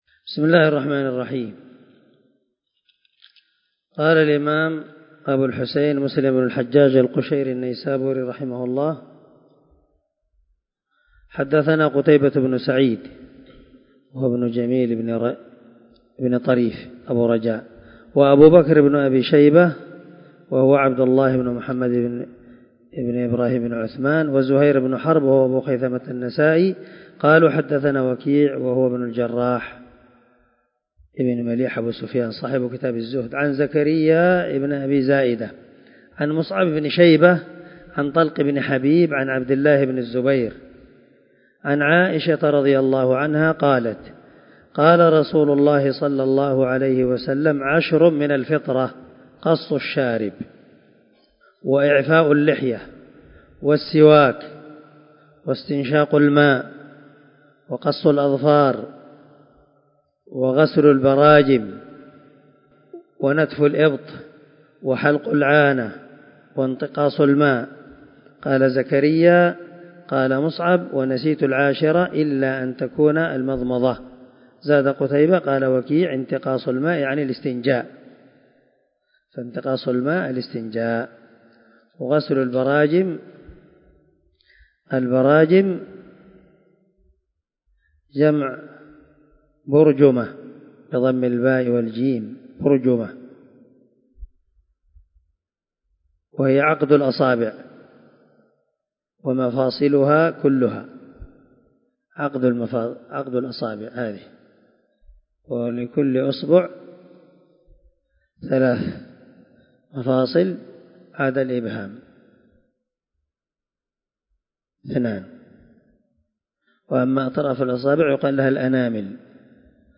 196الدرس 24 من شرح كتاب الطهارة حديث رقم ( 261 ) من صحيح مسلم
دار الحديث- المَحاوِلة- الصبيحة.